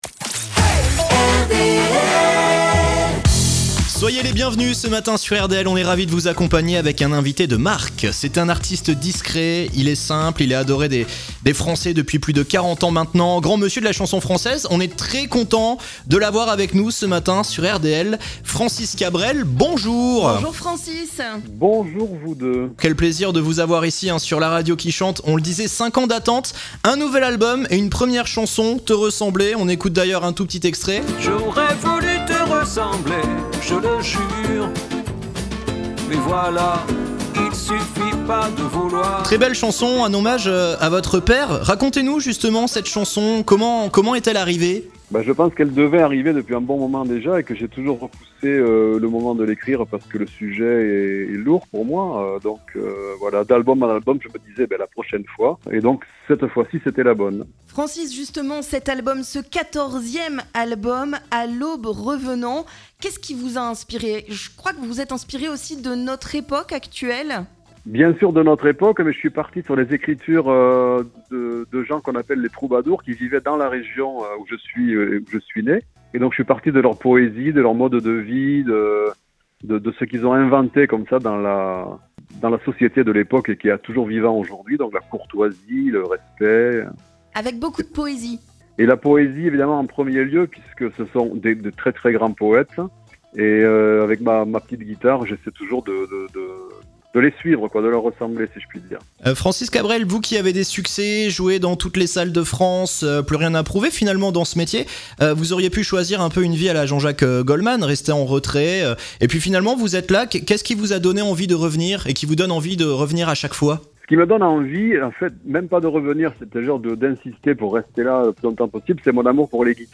A l'occasion de la sortie de son nouvel album, réécoutez l'interview de FRANCIS CABREL sur RDL !